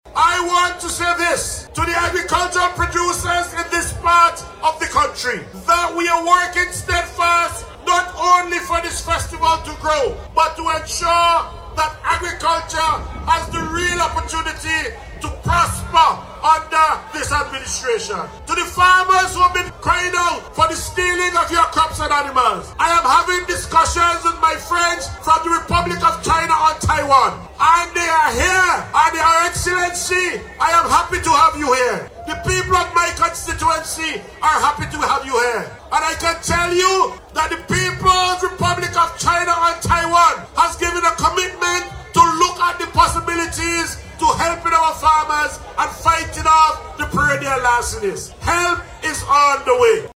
This assurance came from Parliamentary Representative for South Central Windward and Minister of Agriculture, Hon. Israel Bruce, during the Greggs National Heroes Day festivities.